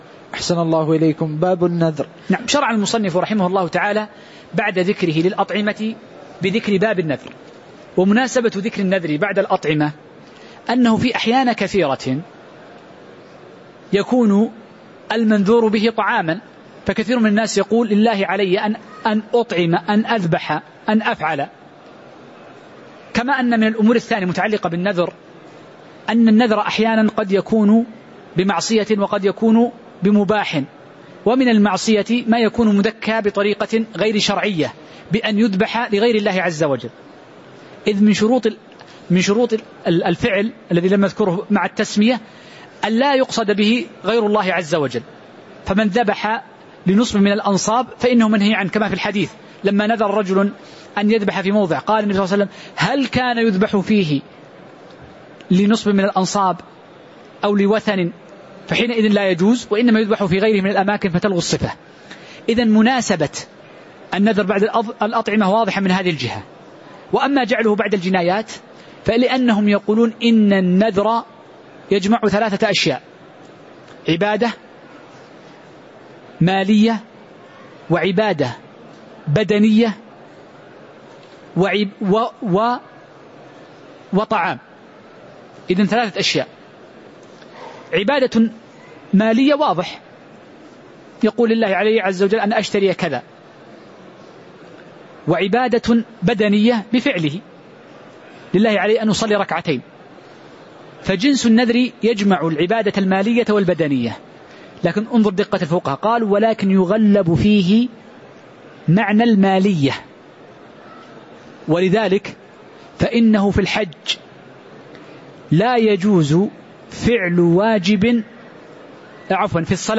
تاريخ النشر ١١ ربيع الأول ١٤٤١ هـ المكان: المسجد النبوي الشيخ